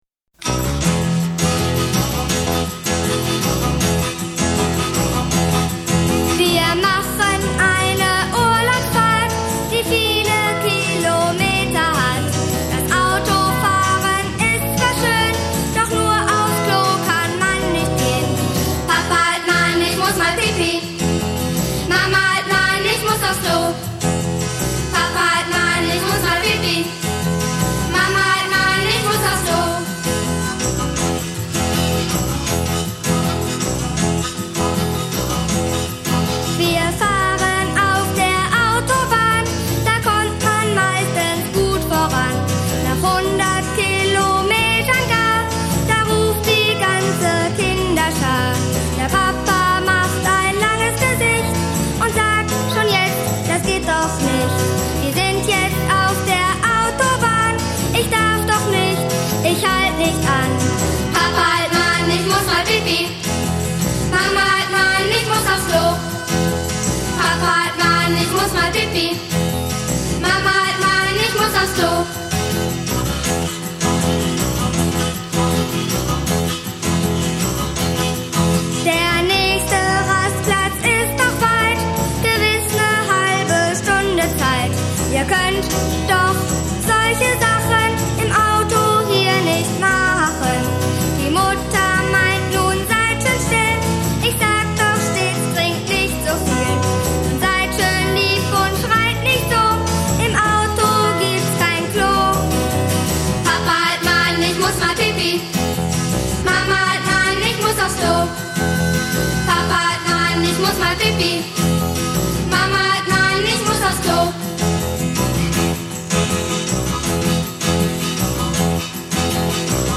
Kinderlieder für unterwegs